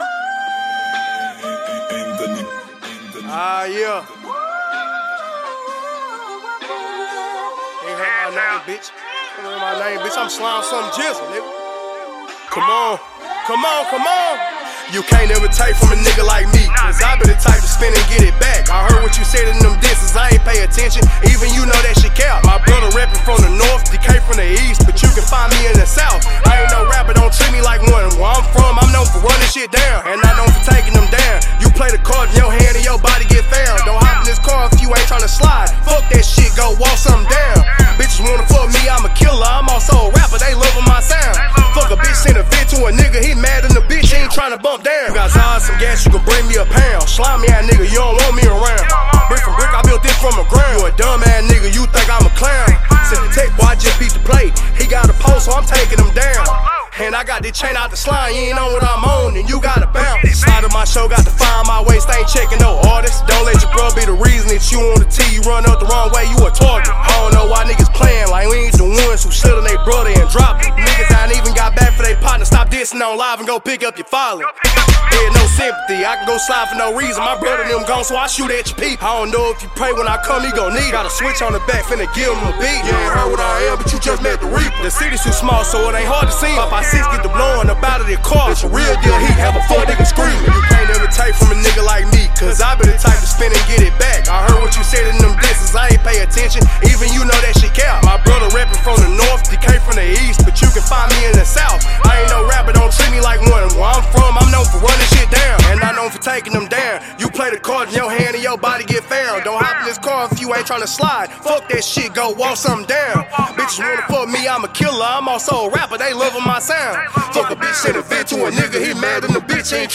Hiphop
Preparing & Vibes in the studio means everything